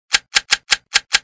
OutofAmmo.ogg